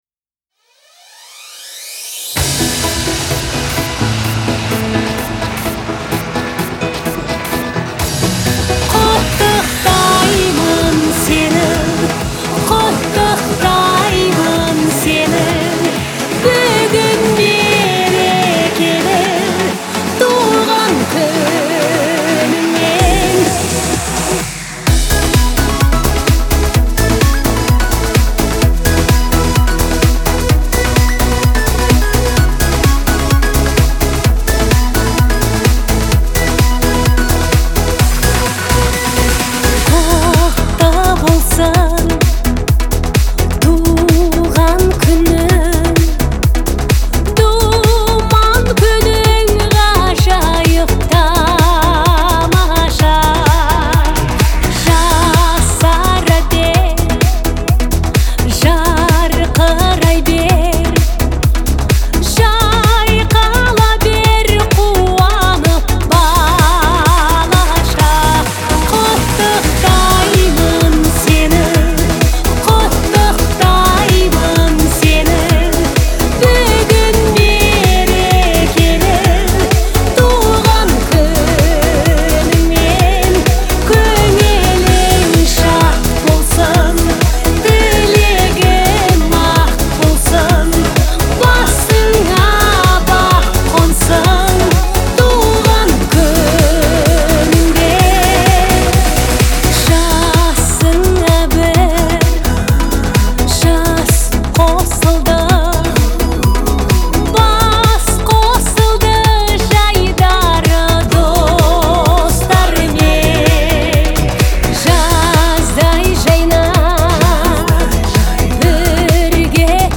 отличается мелодичностью и эмоциональностью